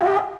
大自然 星号.wav